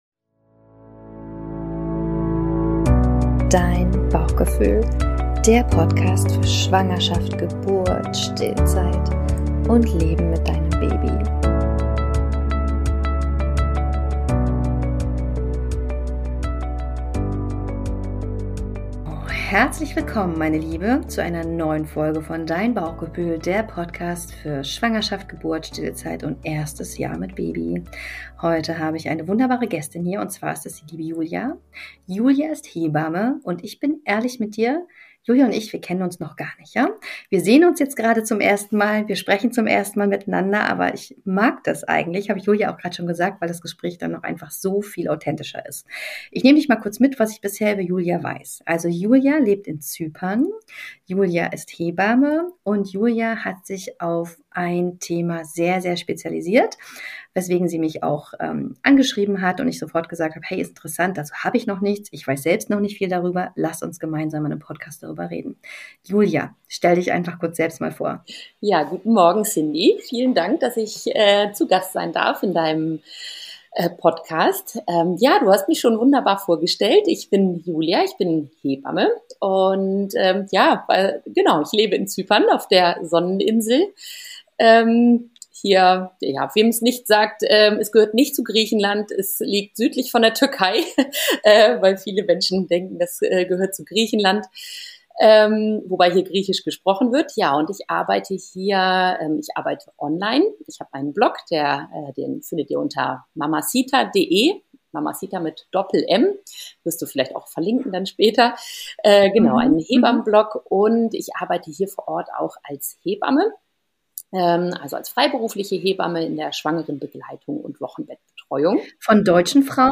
#040 I Hebamme im Interview: Rückbildung nach Kaiserschnitt